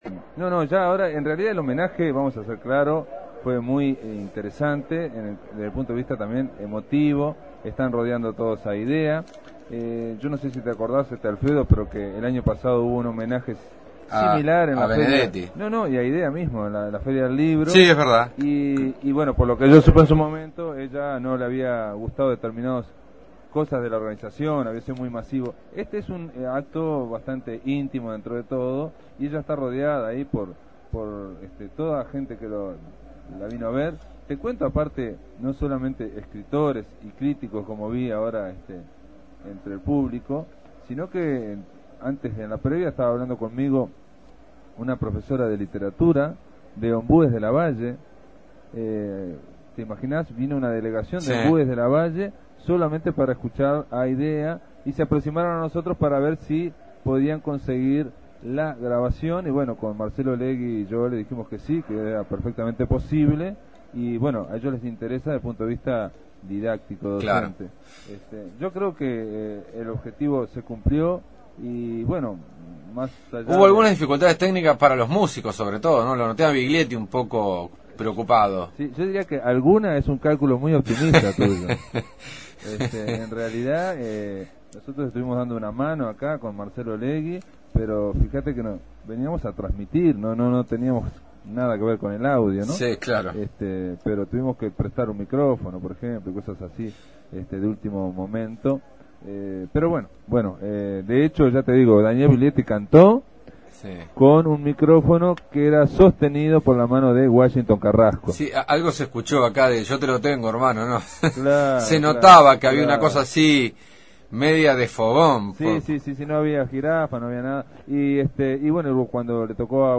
Rescatamos el homenaje a Idea Vilariño en el claustro del Museo Blanes.